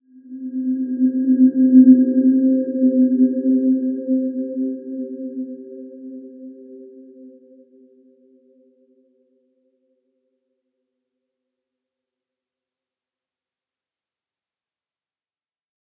Dreamy-Fifths-C4-p.wav